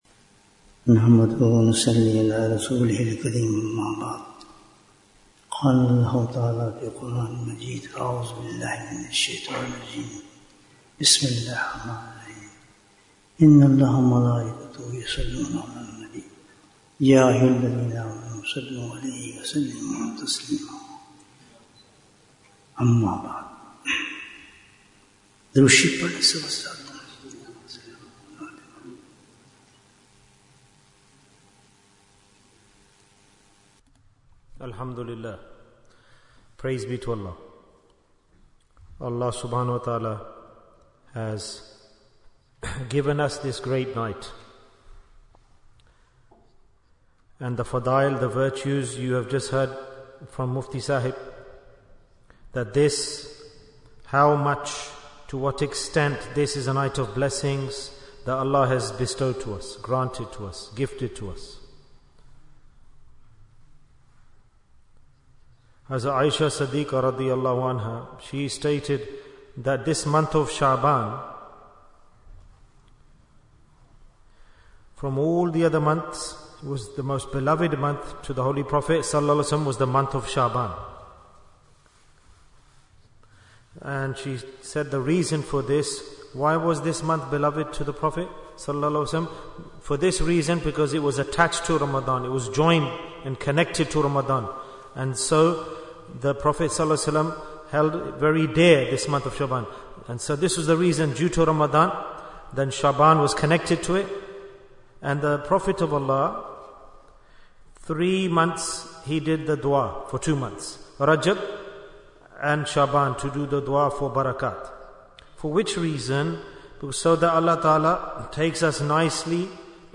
Bayan, 57 minutes13th February, 2025